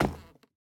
Minecraft Version Minecraft Version snapshot Latest Release | Latest Snapshot snapshot / assets / minecraft / sounds / block / bamboo_wood / step6.ogg Compare With Compare With Latest Release | Latest Snapshot
step6.ogg